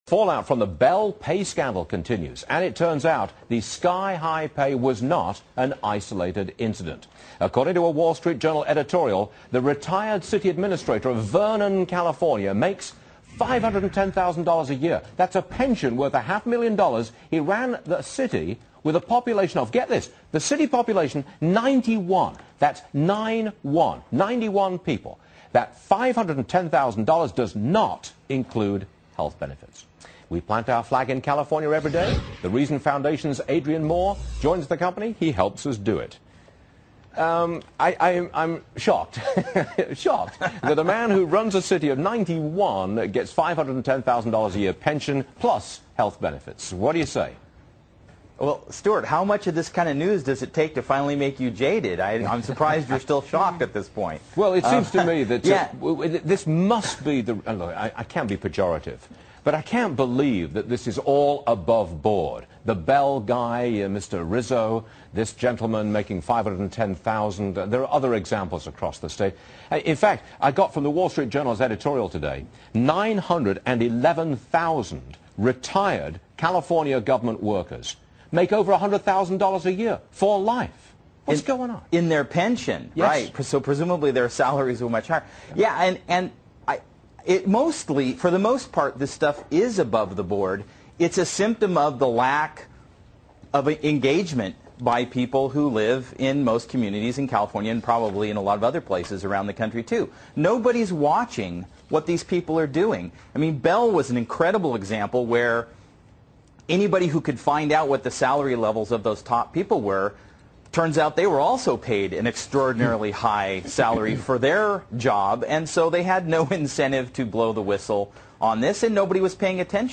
discusses the outlandish pay and pensions of some officials in municipalities across California on Fox Business' Varney & Co. Air date